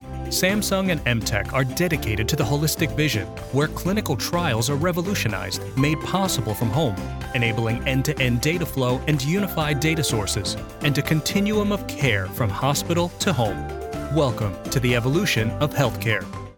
Male
Medical Product Explainer
Words that describe my voice are conversational, relatable, genuine.